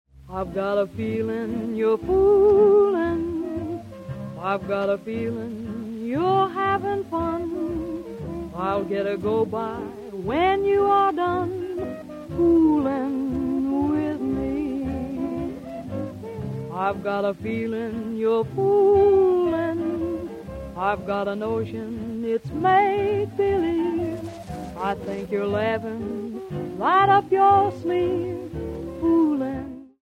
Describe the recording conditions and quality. Original recordings from 1931 - 1941, they're all winners.